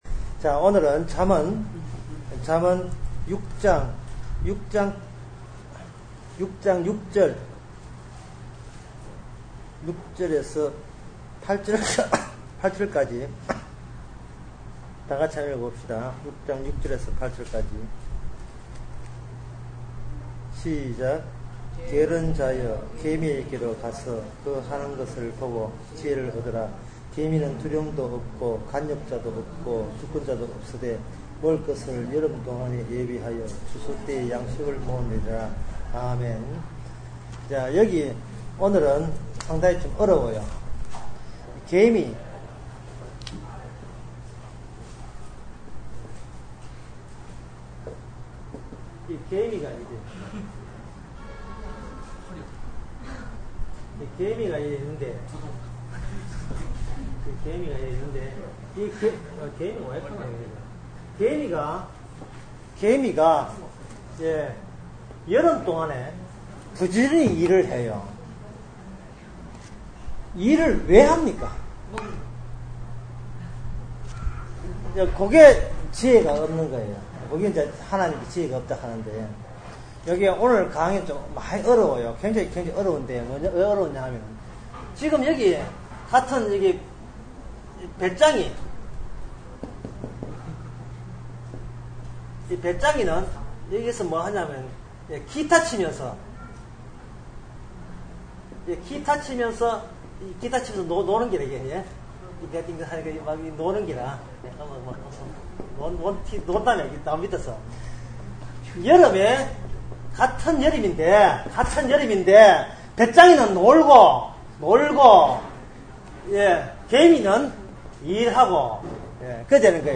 중고등부 성경공부 잠언 14강